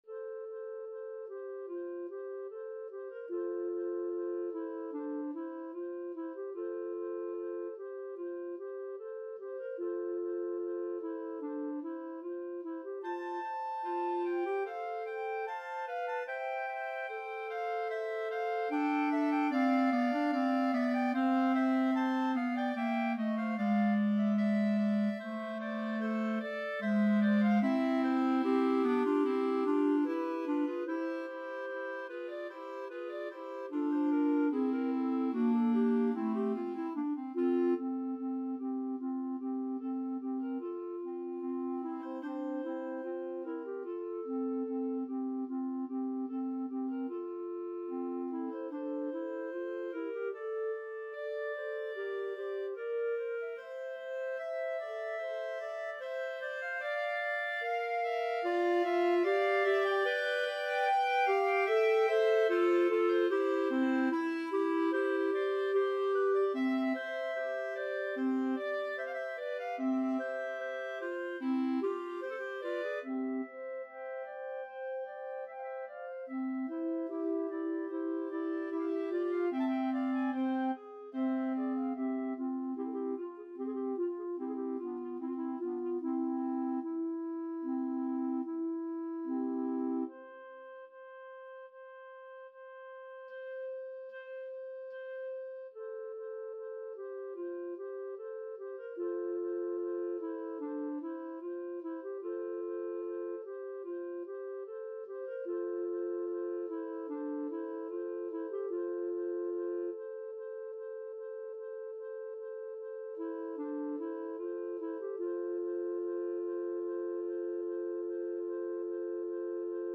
~ = 74 Moderato
4/4 (View more 4/4 Music)
Clarinet Trio  (View more Intermediate Clarinet Trio Music)
Classical (View more Classical Clarinet Trio Music)